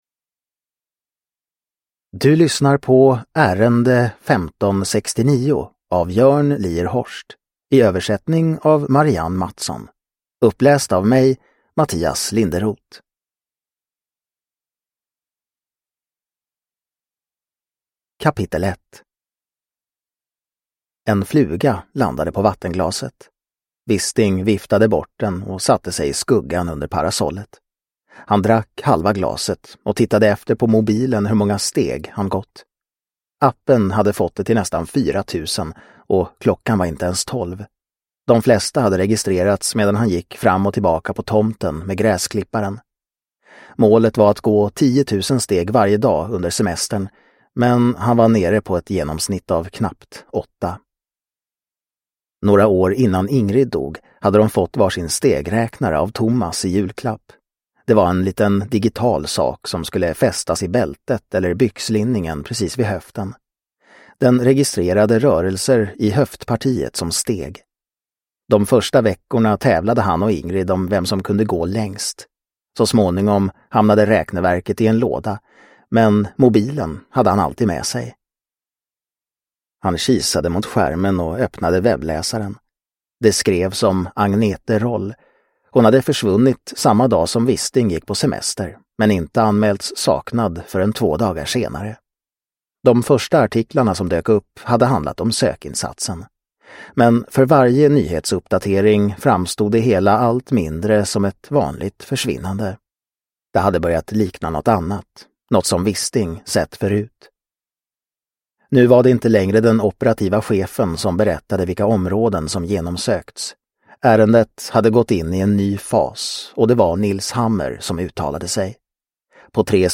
Ärende 1569 – Ljudbok – Laddas ner